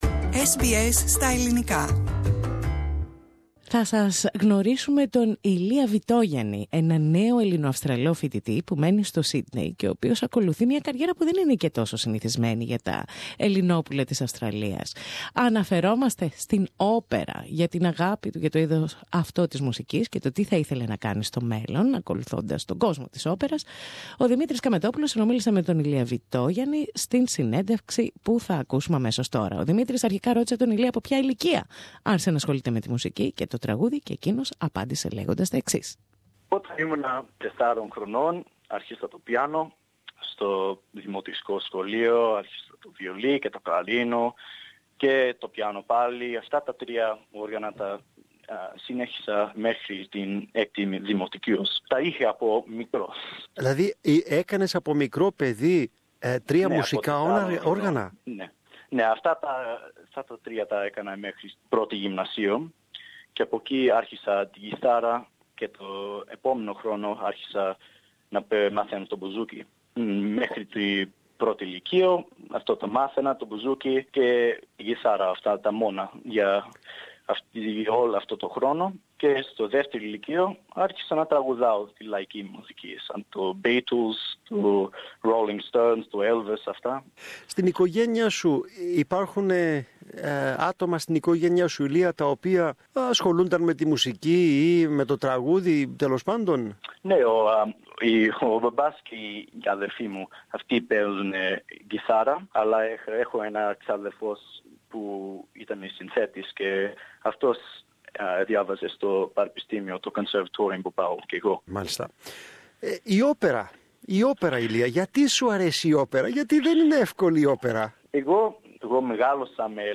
SBS Ελληνικά